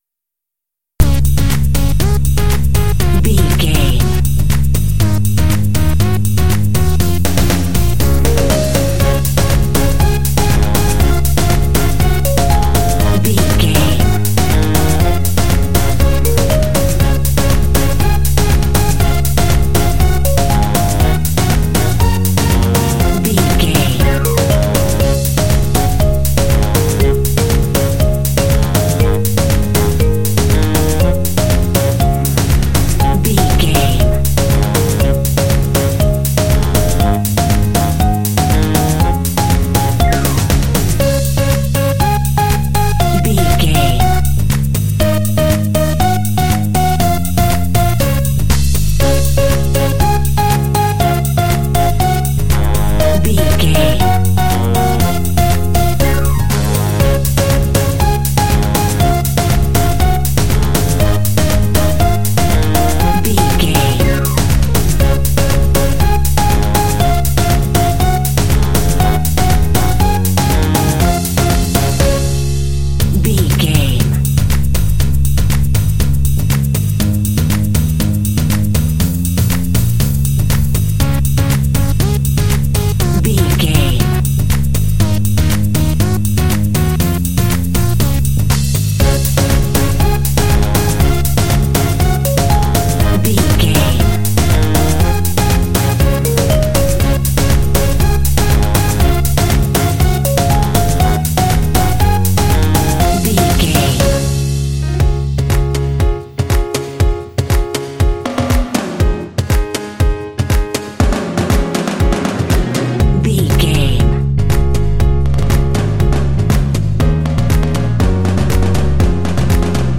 Ionian/Major
D♭
energetic
driving
bouncy
synthesiser
drums
percussion
electric piano
saxophone
bass guitar
pop